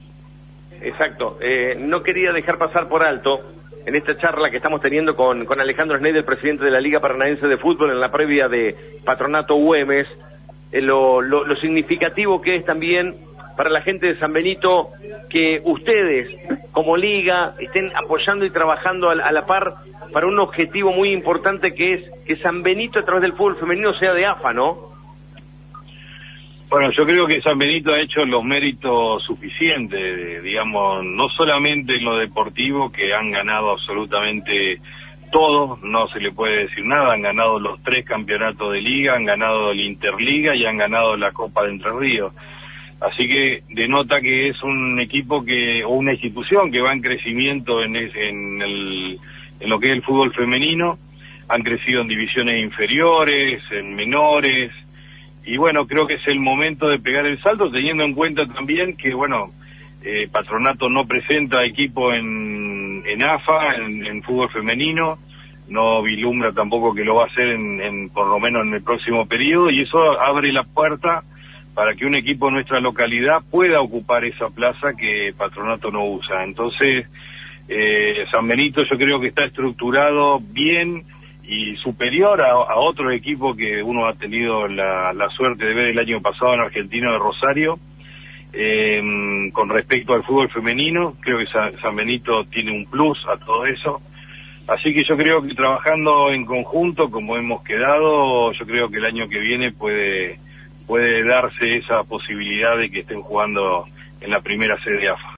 Linda charla